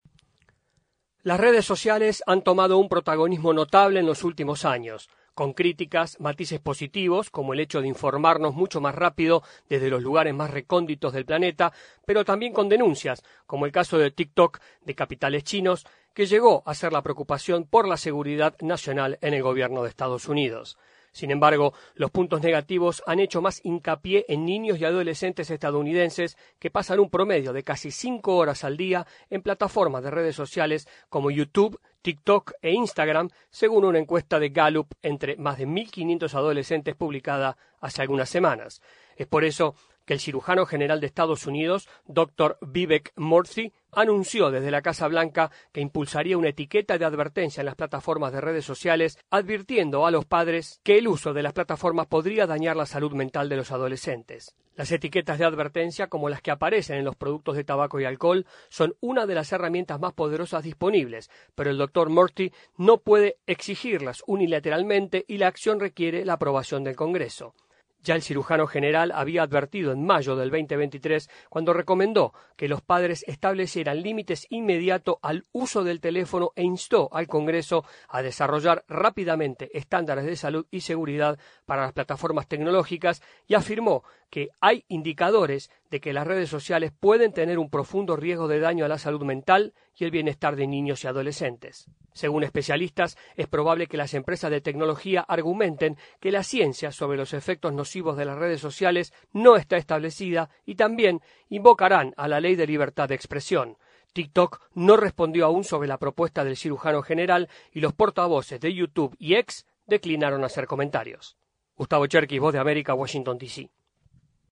desde la Voz de América en Washington DC